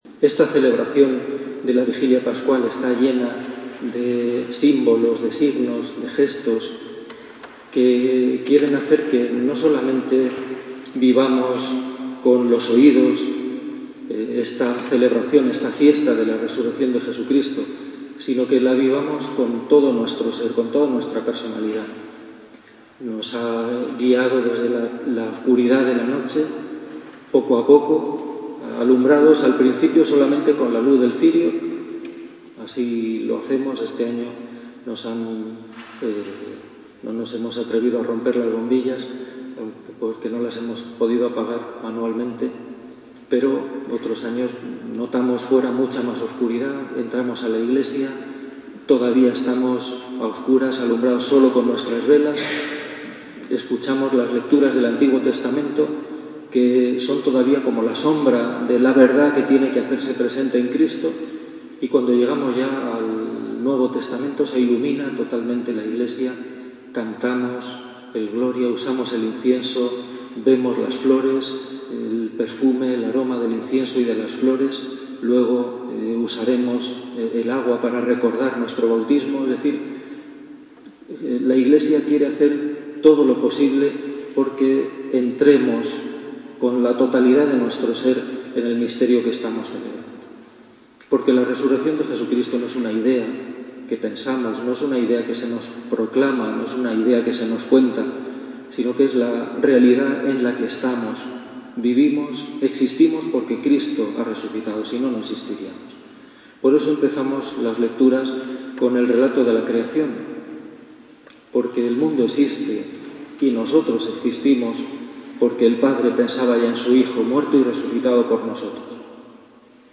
HomiliaVigiliaPascual.mp3